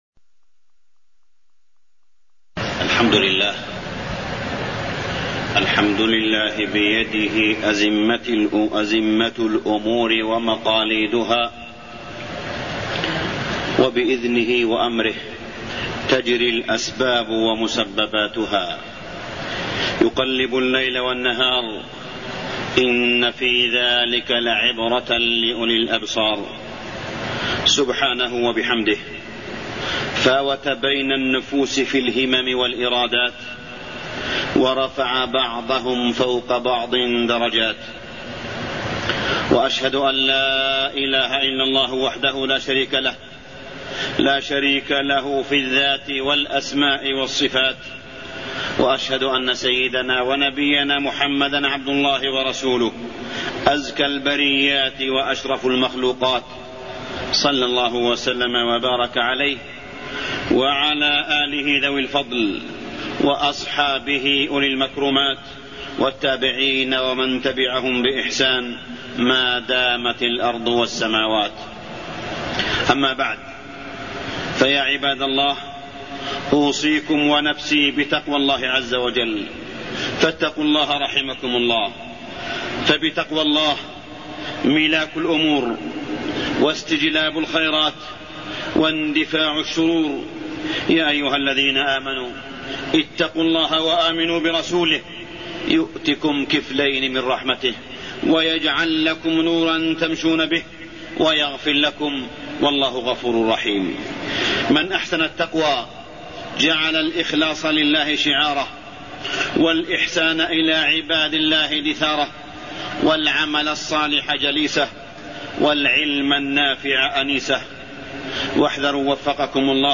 تاريخ النشر ٢٥ ذو الحجة ١٤٢٠ هـ المكان: المسجد الحرام الشيخ: معالي الشيخ أ.د. صالح بن عبدالله بن حميد معالي الشيخ أ.د. صالح بن عبدالله بن حميد الحذر من اليأس